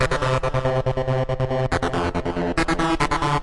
描述：用reFX Vanguard制作的旋律。声音一。
Tag: 高潮 轨道 恍惚栅极 捻线机 DJ 标题 舞蹈 melodics 旋律 恍惚